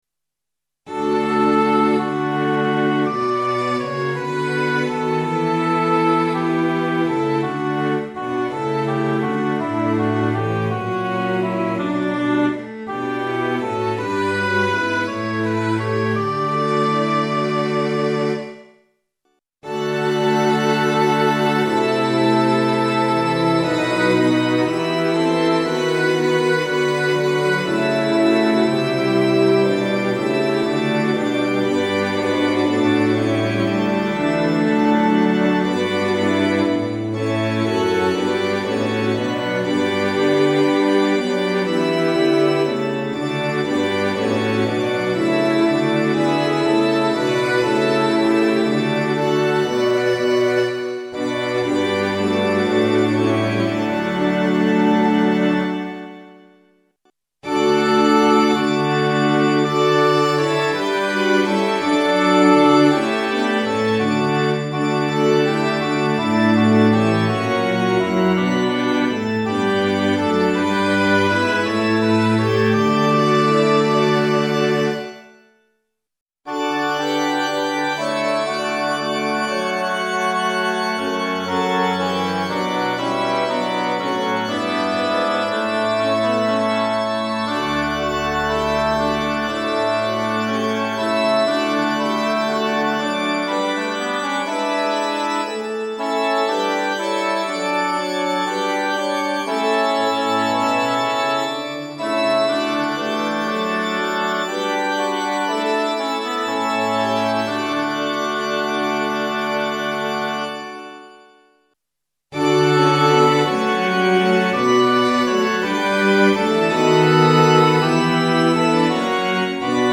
混声四部合唱+器楽 Four-part mixed chorus with Instruments
0.9.9.3 D Choir(S,A,T,B)